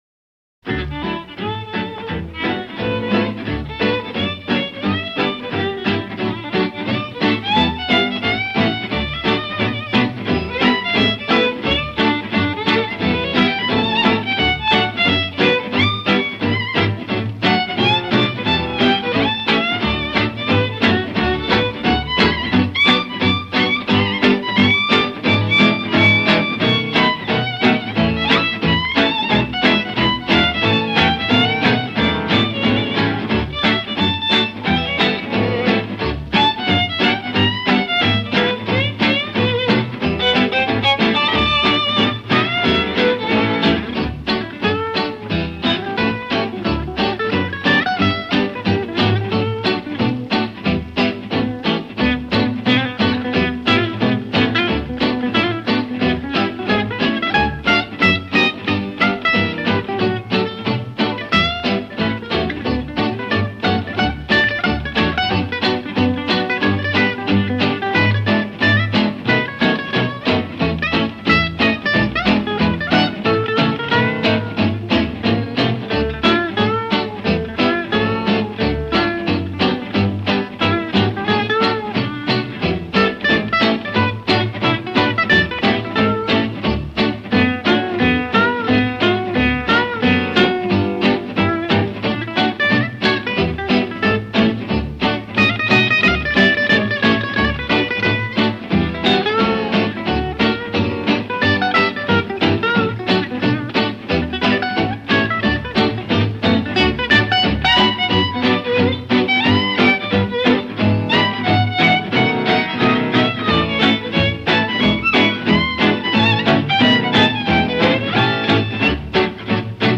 violin
double bass
rhythm guitar